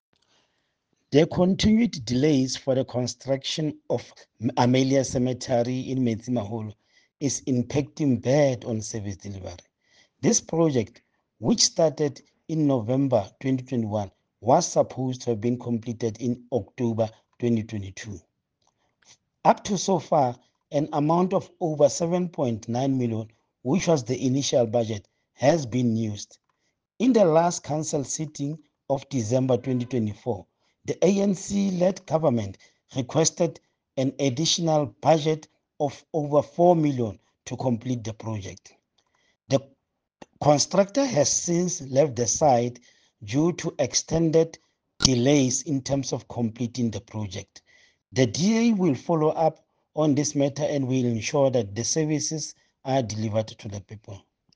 Issued by Stone Makhema – DA Councillor Metsimaholo Local Municipality
Sesotho soundbites by Cllr Stone Makhema. Please see the pictures here, here, and here.